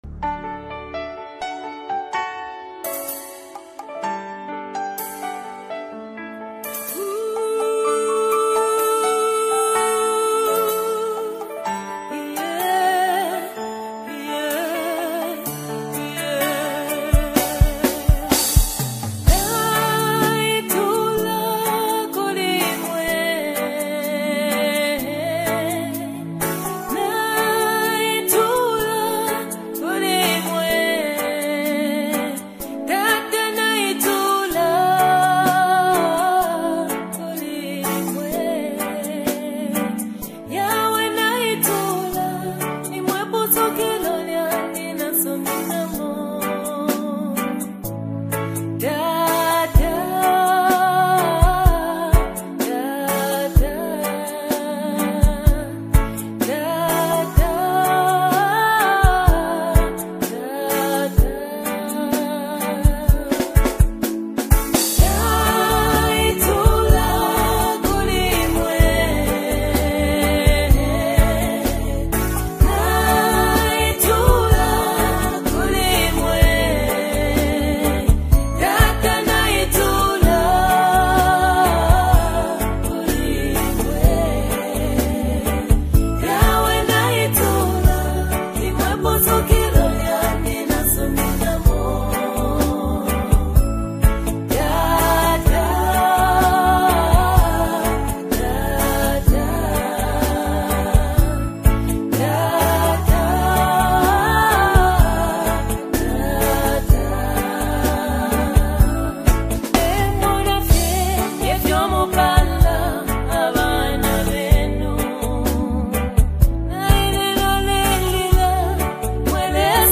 DEEP ETHNIC WORSHIP EXPRESSION | 2025 AFRICAN GOSPEL
Rooted in rich African rhythms and heartfelt devotion